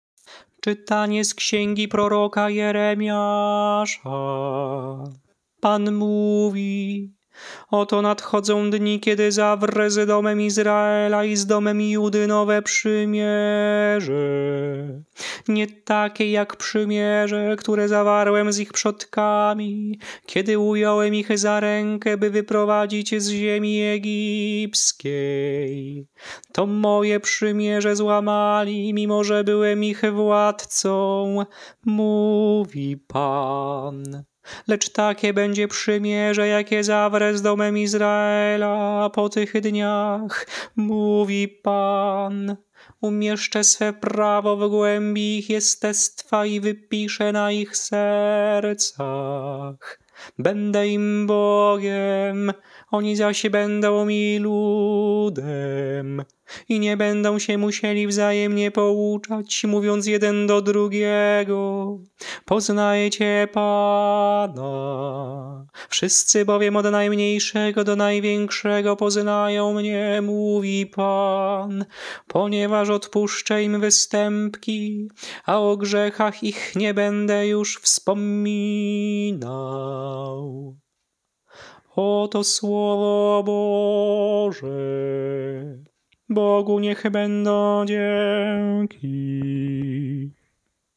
Śpiewane lekcje mszalne – V Niedziela Wielkiego Postu
Melodie lekcji mszalnej przed Ewangelią na V Niedzielę Wielkiego Postu: